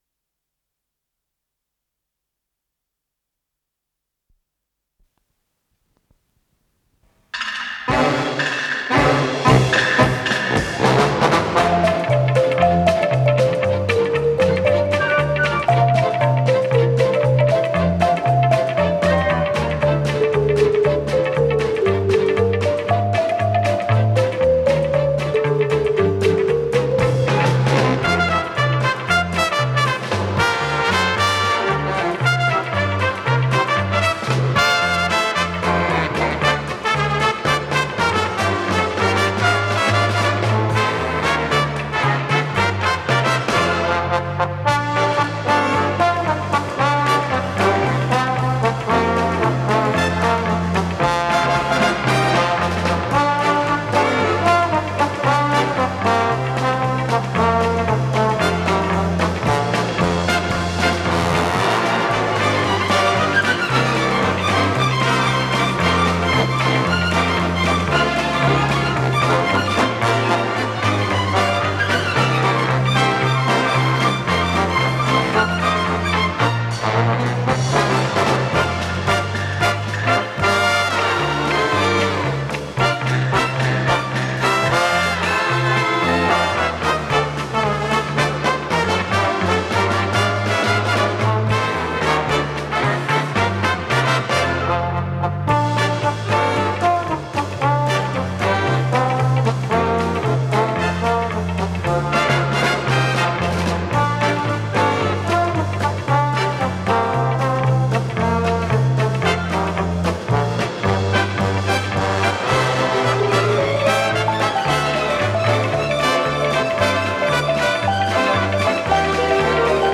ПодзаголовокПьеса для эстрадного оркестра
Соло на маримбафоне
ВариантДубль моно